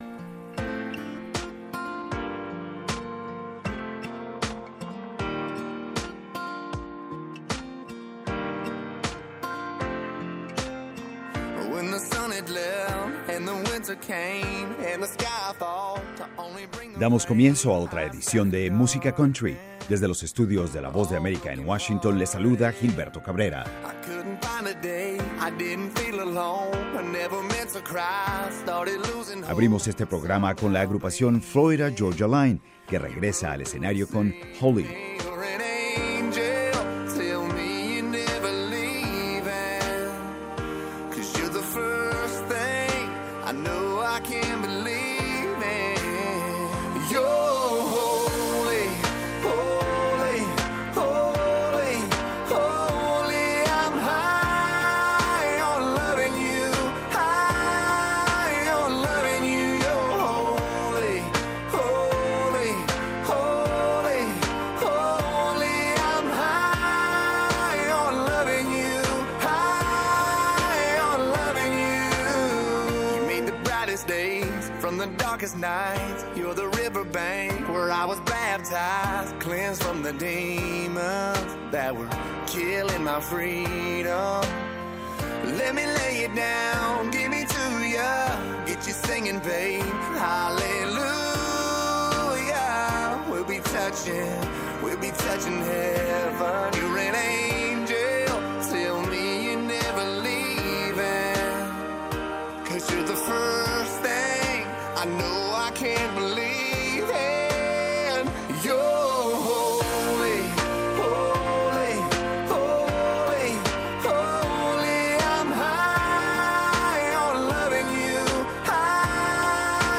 Música Country